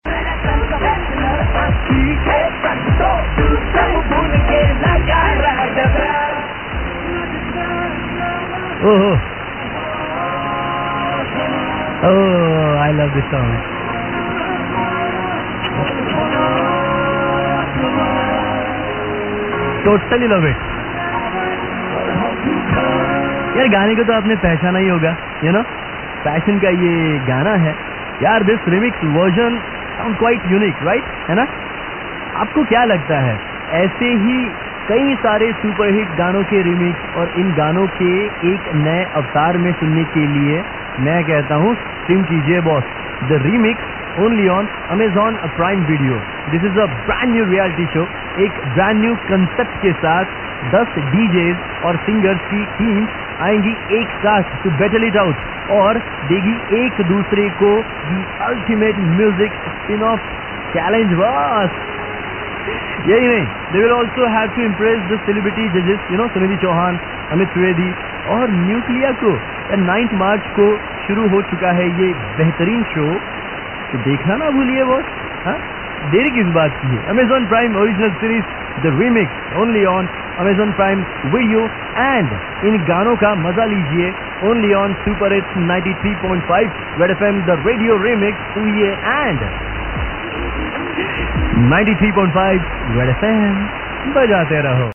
remix version